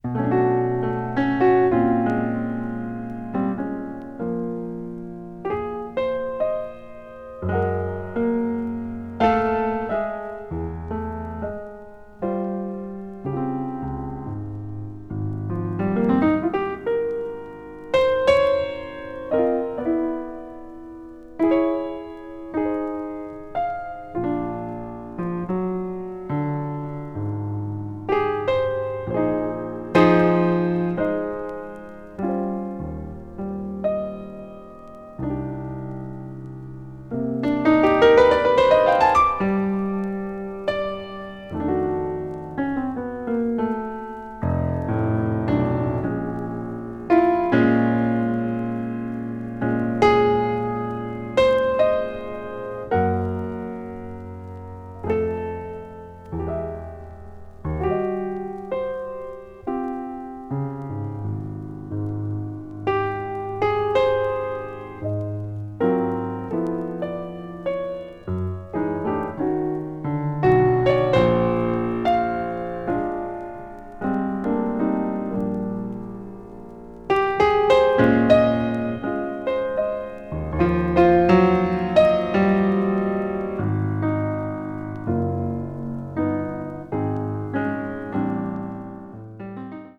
感情の赴くままに美しく繊細、かつエネルギッシュな旋律を即興的に紡ぎ上げた、全くもって素晴らし過ぎる演奏。
contemporary jazz   piano solo   post bop   spiritual jazz